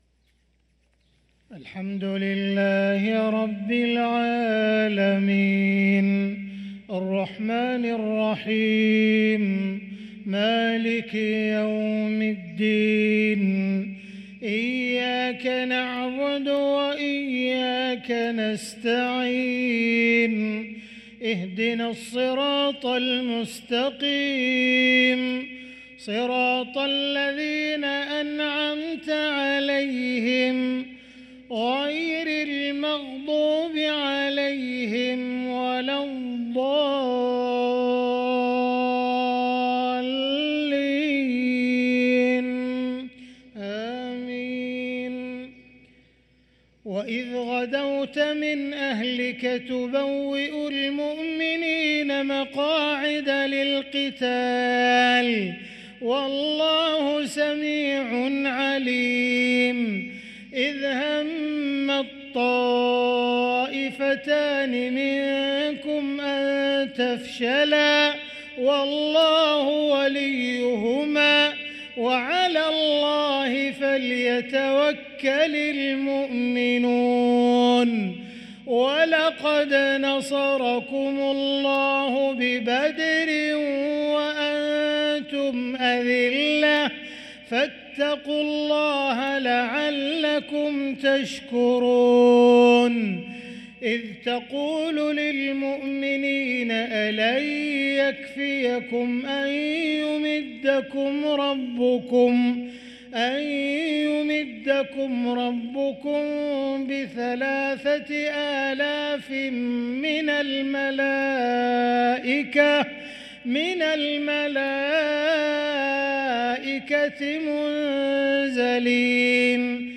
صلاة الفجر للقارئ عبدالرحمن السديس 2 جمادي الأول 1445 هـ
تِلَاوَات الْحَرَمَيْن .